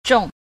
a. 重 – zhòng – trọng
zhong-1.mp3